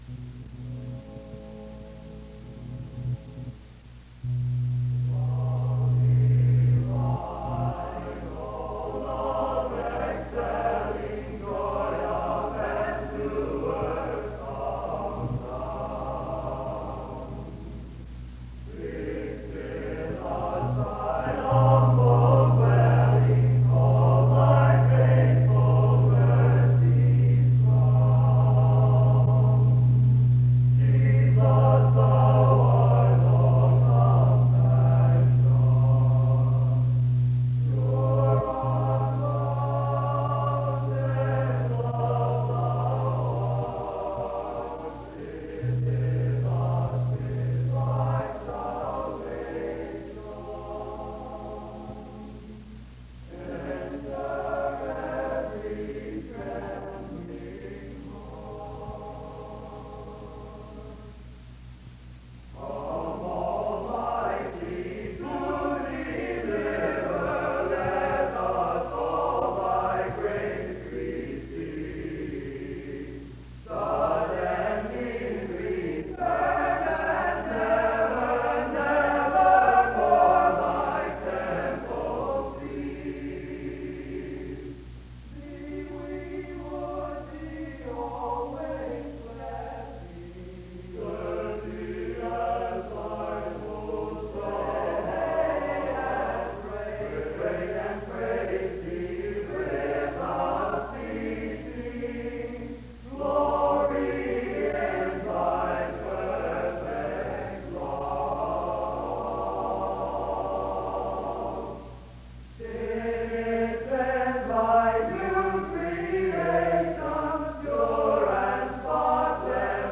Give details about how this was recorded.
The service begins with a period of singing (0:00-2:56). He concludes by illustrating that the strongest emotional need is to love and to be loved (13:27-20:06). The service closes in music (20:07-22:08).